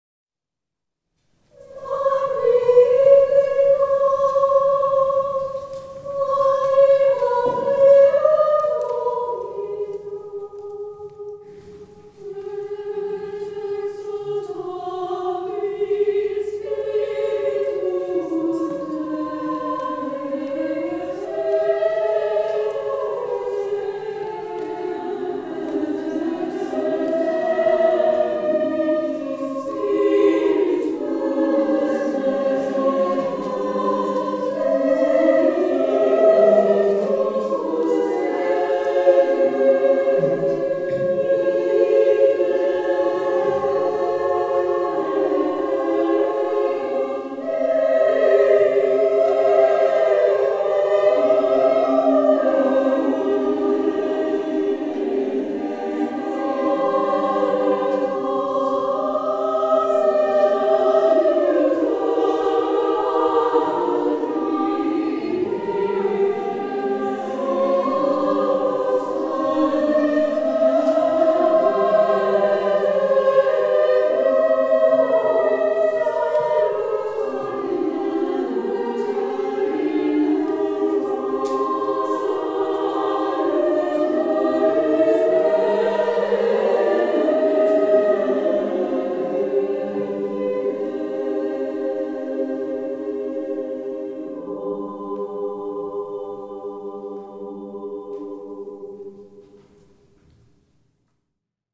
This little gem is special partly because it was composed in its original form for treble voices. It is a setting of just a few verses of the Magnificat, the most famous of biblical canticles.
Performed by Voci Women’s Vocal Ensemble (Oakland, CA)
This performance was recorded live at St. Joseph Basilica, Alameda, CA in Dec. 2017.